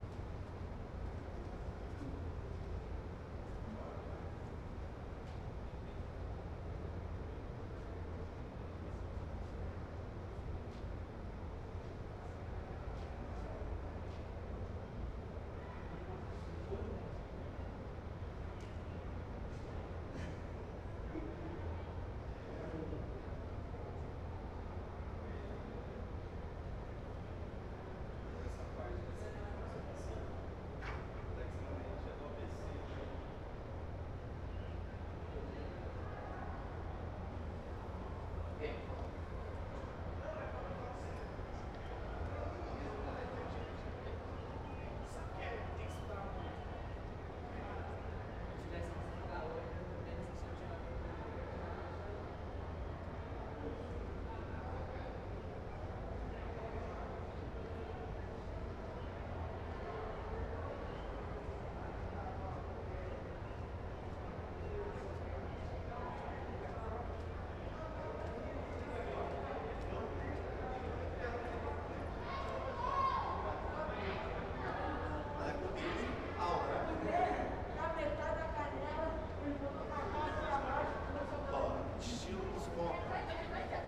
Crianças em ambiente interno Ambiente interno , Crianças , Pessoas , Porta , Vozerio
Surround 5.1
CSC-15-007-LE - Crianças em ambiente interno.wav